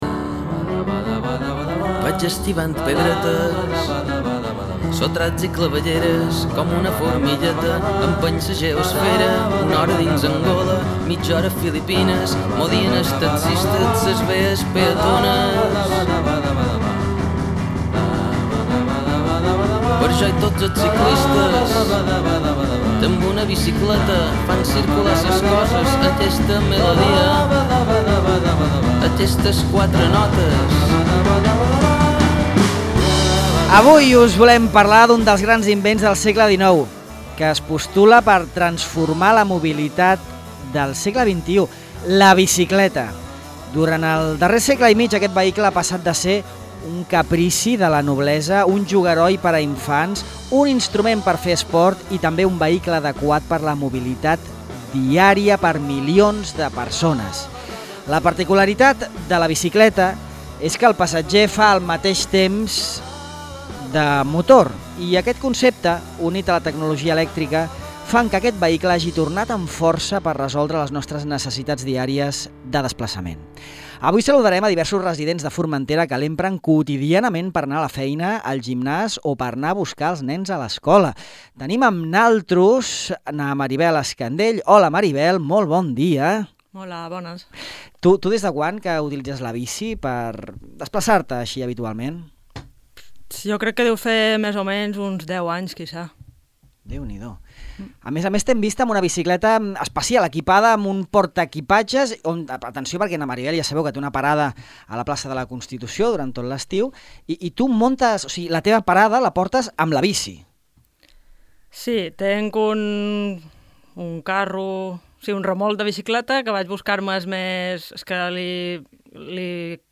En el Dia Mundial sense Cotxes Ràdio Illa ha convidat a residents a Formentera que empren la bicicleta en els seus desplaçaments quotidians. Amb ells repassam l’experiència de moure’s cada dia pedalant i compartim les valoracions sobre el paper de la bicicleta en la via pública i com fer-lo més segur i amable.